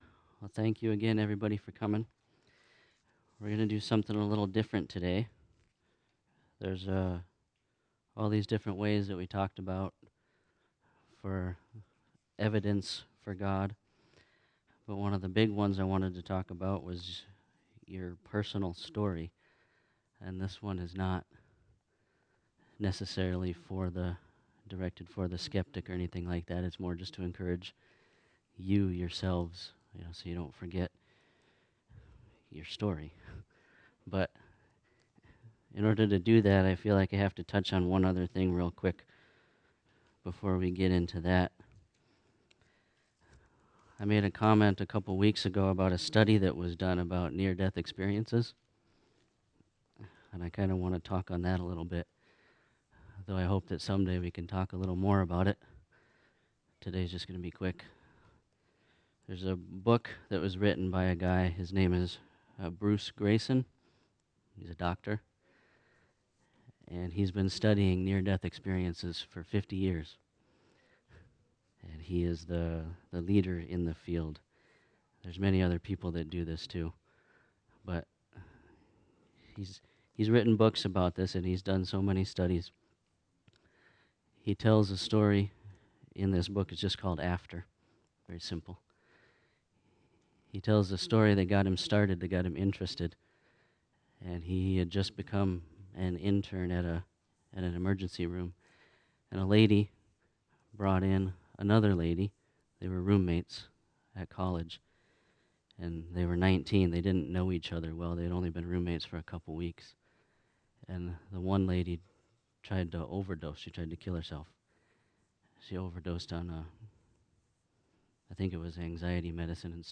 This is a special class-series Friendship church is providing for all who want to not just say they believe in God, but to prove He exists.